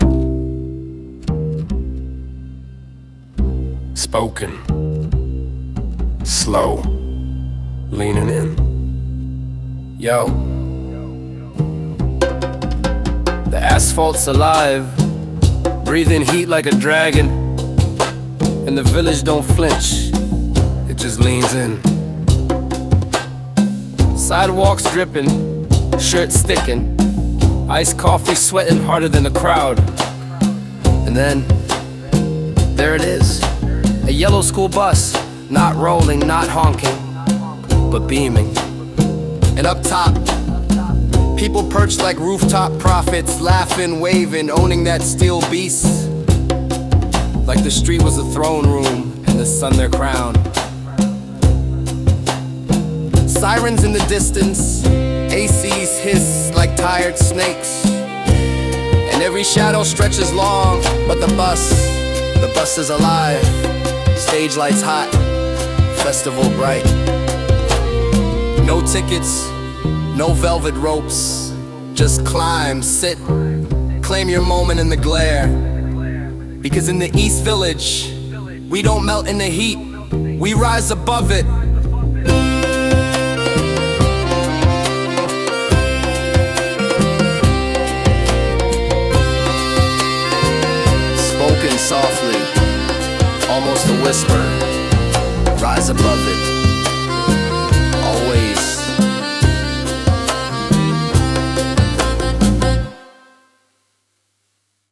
Style: Slam poetry with upright bass, light conga, and distant street‑sound samples (sirens, hum of AC, footsteps). Moderate tempo, heavy spoken‑word cadence.